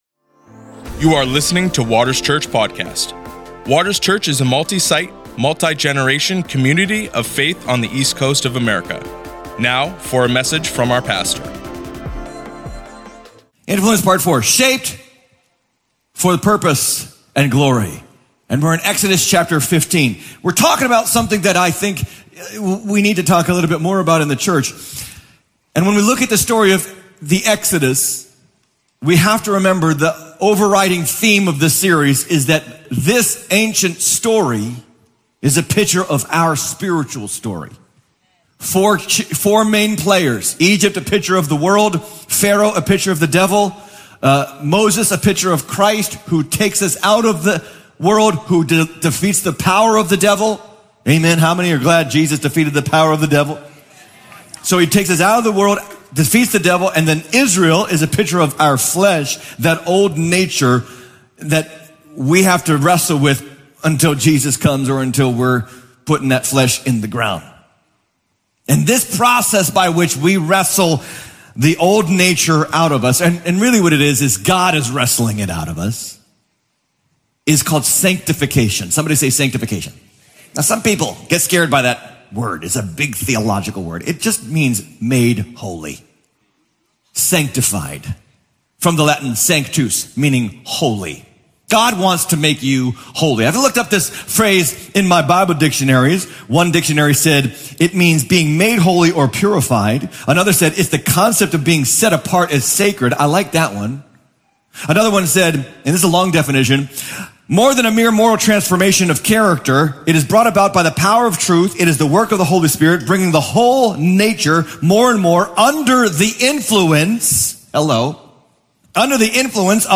You can listen to Waters Church messages at anytime from anywhere!